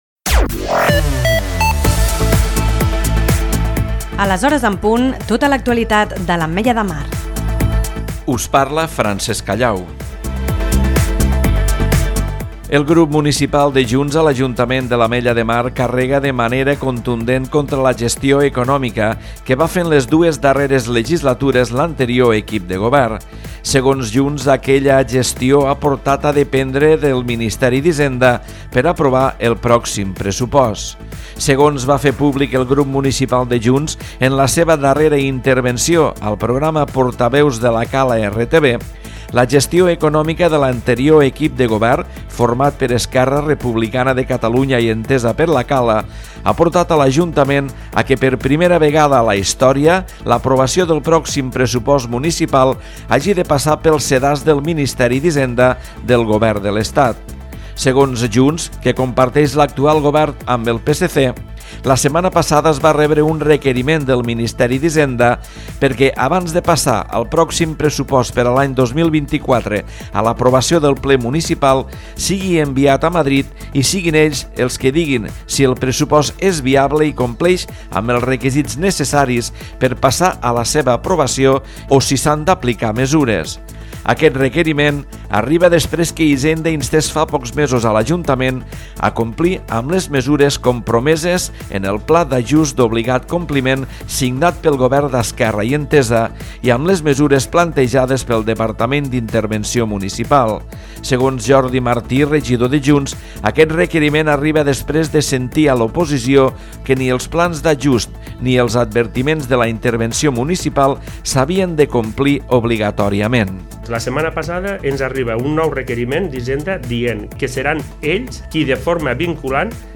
Butlletí Informatiu 16/11/2023